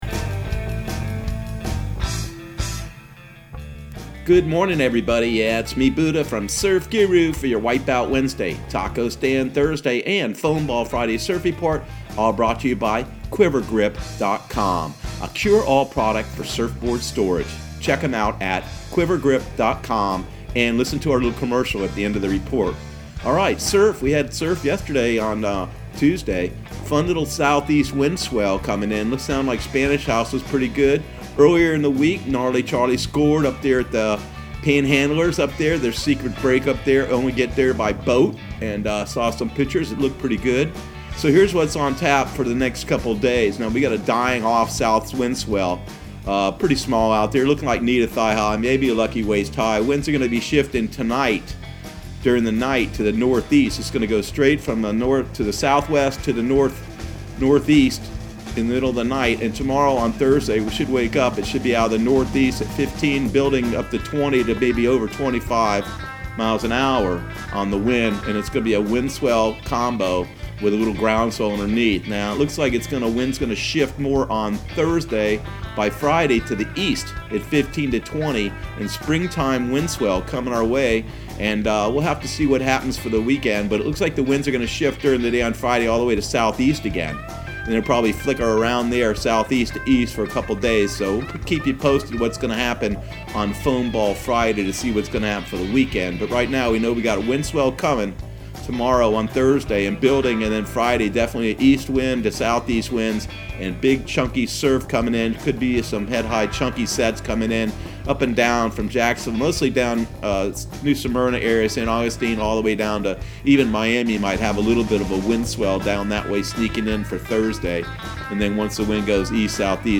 Surf Guru Surf Report and Forecast 04/15/2020 Audio surf report and surf forecast on April 15 for Central Florida and the Southeast.